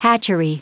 Prononciation du mot hatchery en anglais (fichier audio)
Prononciation du mot : hatchery